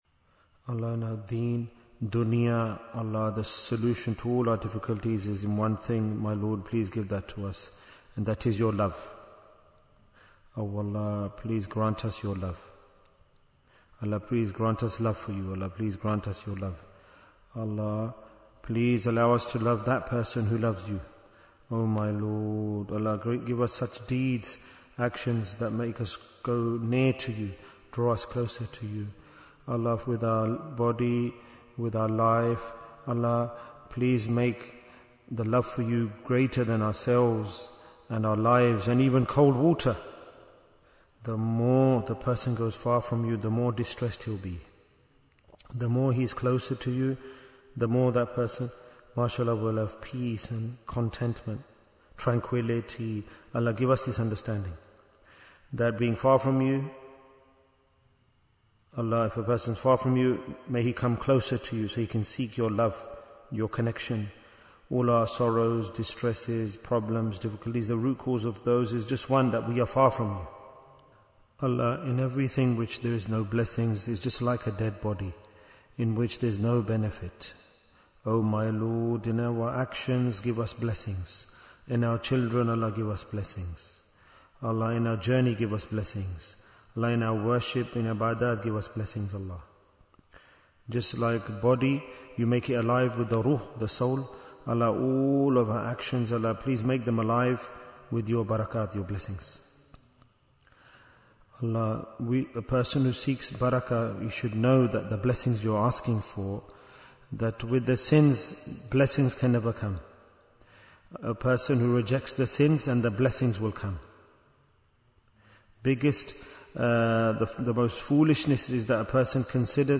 Why is Tazkiyyah Important? - Part 18 Bayan, 96 minutes8th February, 2026